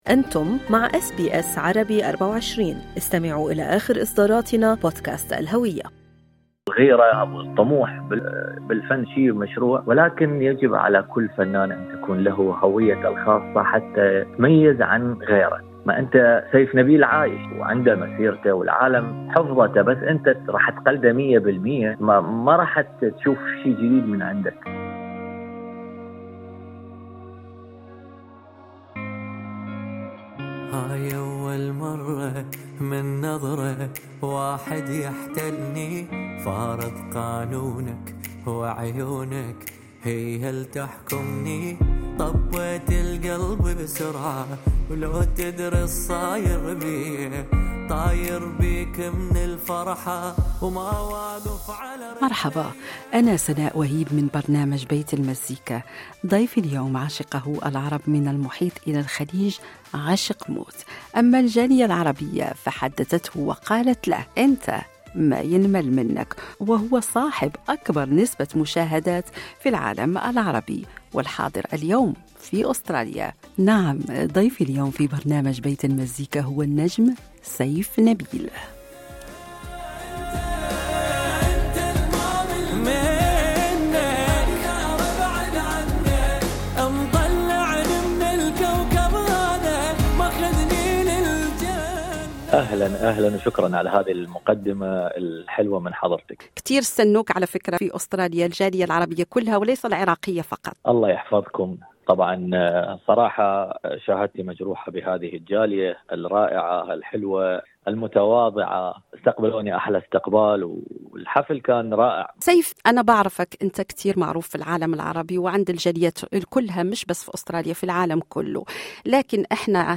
وفي لقاء حصري مع برنامج بيت المزيكا أعرب سيف عن امتنانه لما شهده من حفاوة استقبال وعن بالغ سعادته لنجاح حفله الأول بسيدني والذي سيليه حفلات أخرى.